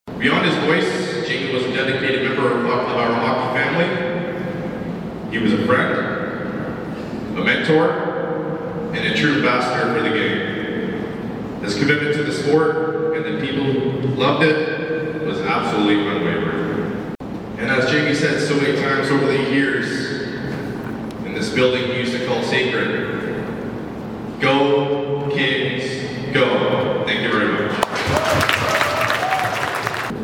The opening home game of the first round playoff series between the Pembroke Lumber Kings and The Smiths Falls Bears began with a special ceremony this past Sunday night.
Before a hushed PMC crowd of nearly 800
read a heartfelt speech